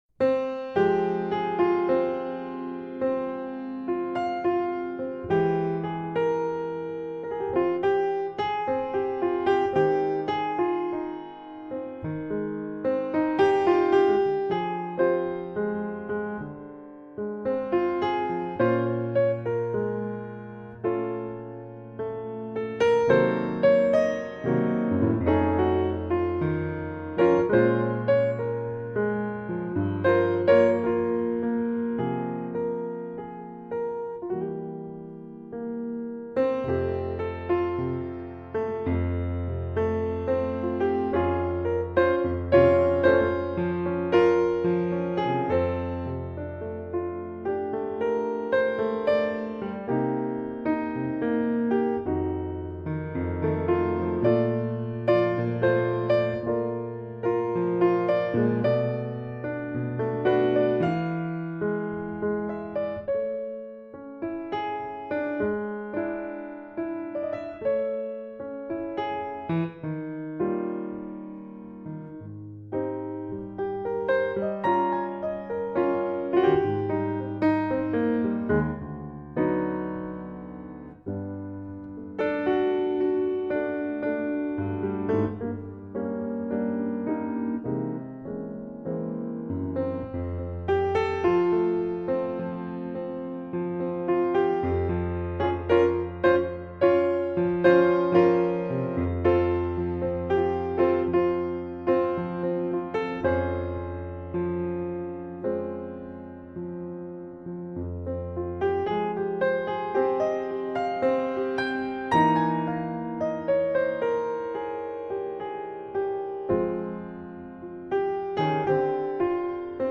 Jazz
Altsax
Gitaar
Piano
Drums